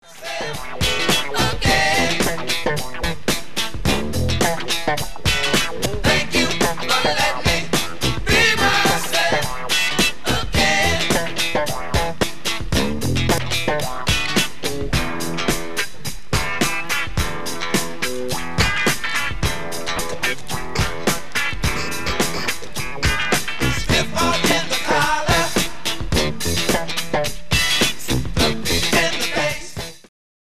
lead vocals, keyboards, everything
guitar, background vocals
lead and background vocals
drums, background vocals
saxophone, background vocals
trumpet, background vocals
bass guitar, background vocals